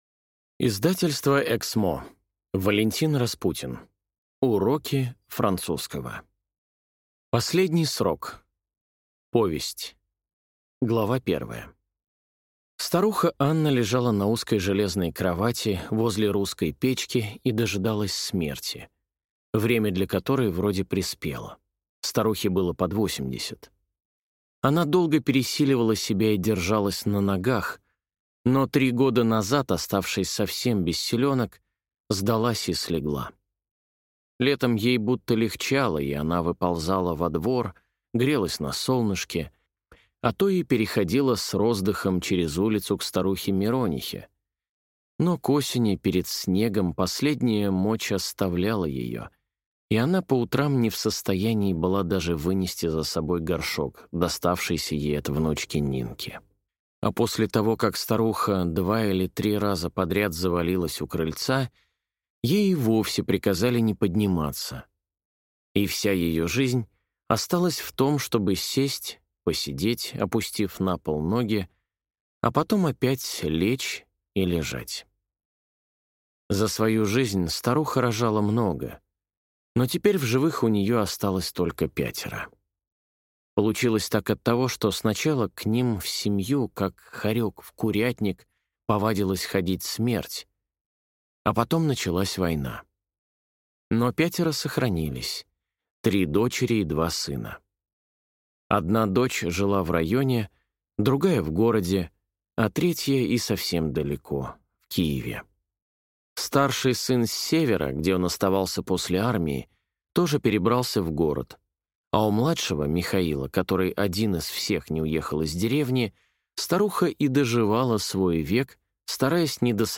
Аудиокнига Уроки французского | Библиотека аудиокниг